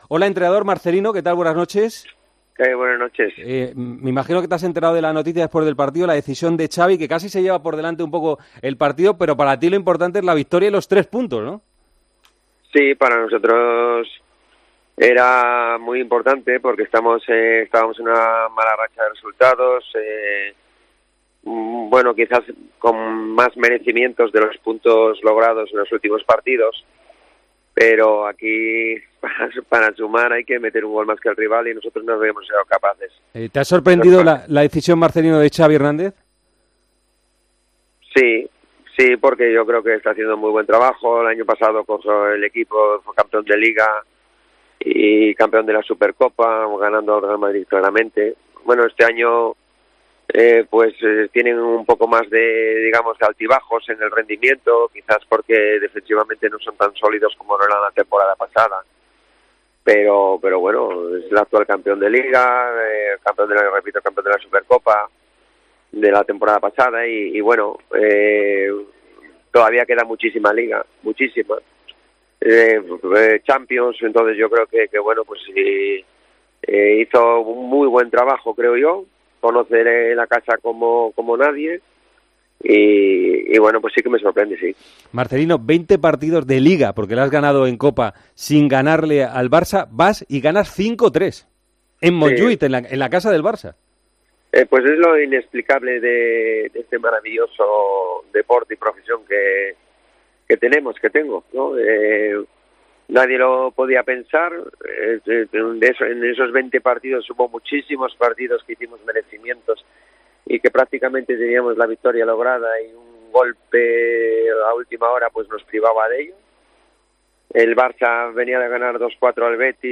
El entrenador del Villarreal valoró en Tiempo de Juego la victoria de su equipo contra el Barcelona.